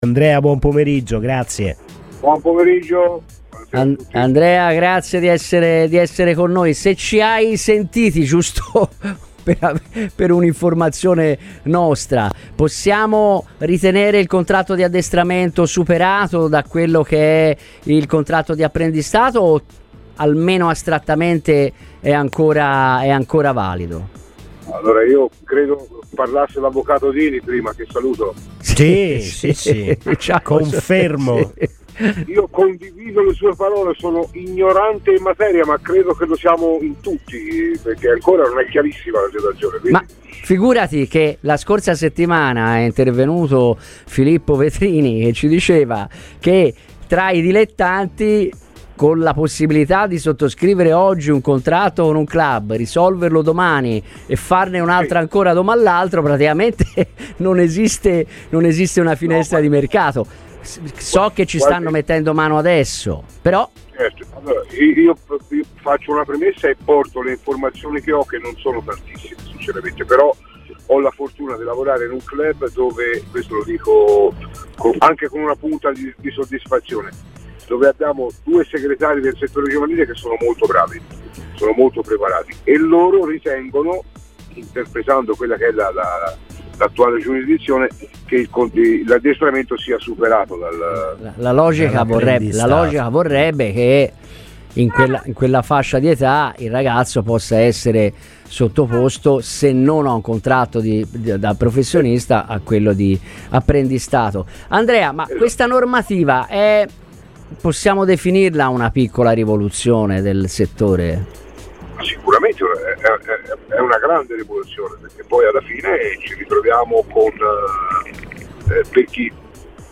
ASCOLTA L'INTERVISTA INTEGRALE SUL PODCAST